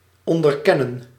Ääntäminen
France: IPA: [i.dɑ̃.ti.fje]